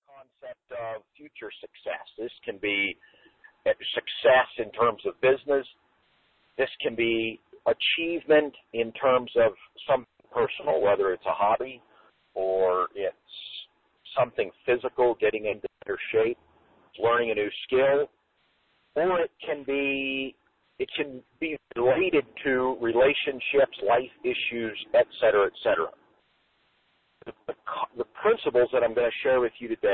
Learning to overcome failings and obstacles is what your needing to succeed online. This is an audio course that will teach you lessons in doing this.